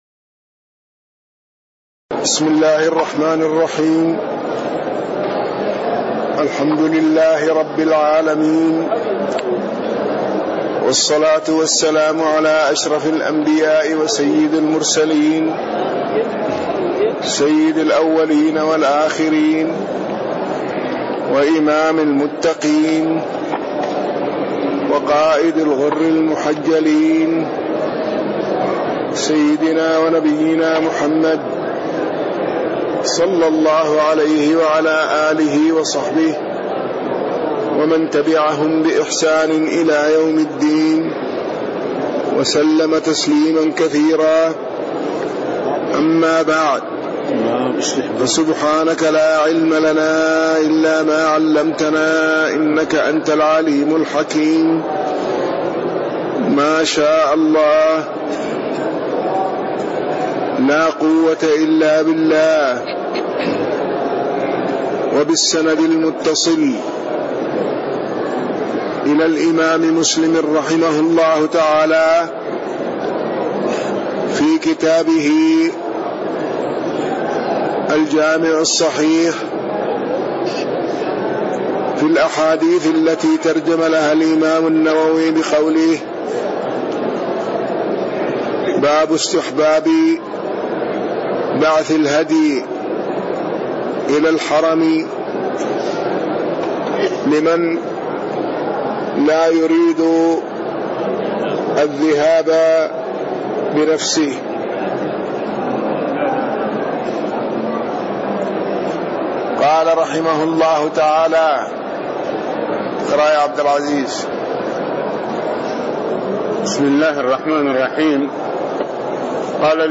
تاريخ النشر ٢٩ ربيع الأول ١٤٣٤ هـ المكان: المسجد النبوي الشيخ